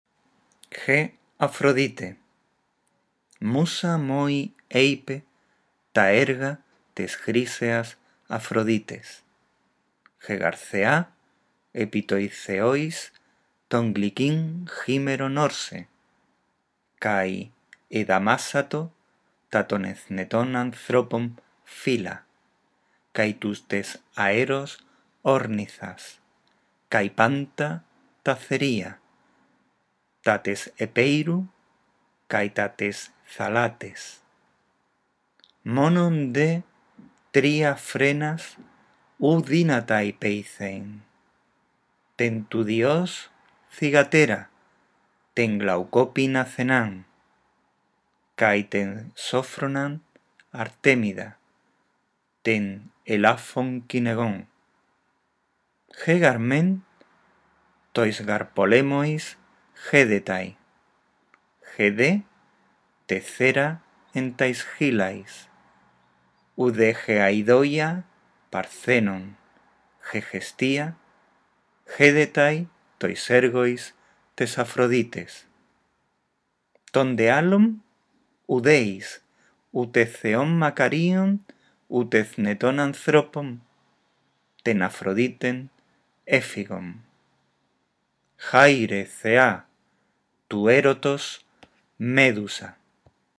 Lee con atención y en voz alta el texto sobre Afrodita; está inspirado en el himno homérico a la diosa y en él se describe su dominio sobre toda la naturaleza. Después, escucha este archivo de audio y repite la lectura.